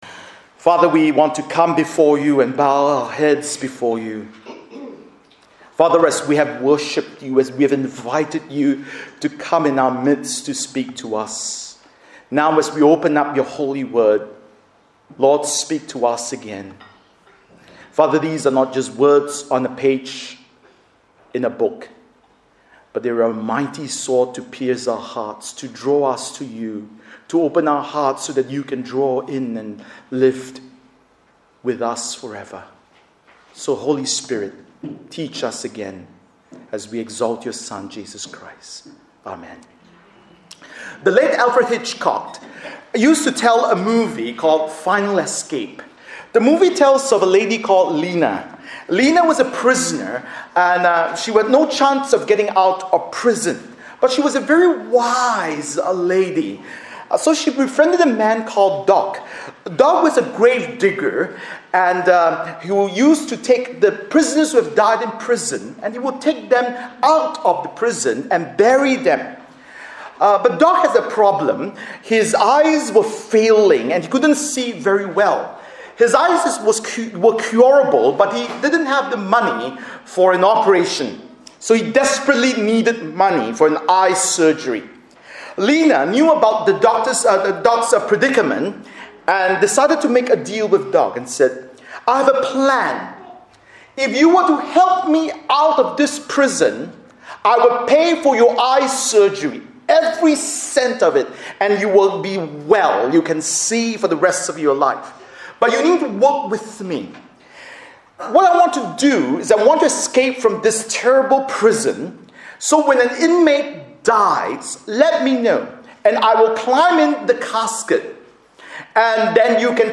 Bible Text: Matthew 4:1-11 | Preacher